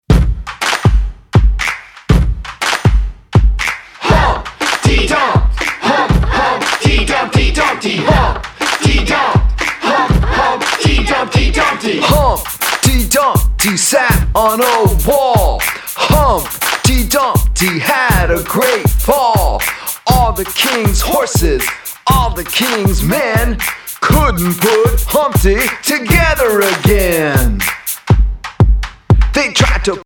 fun, upbeat, dance, exercise and creative movement songs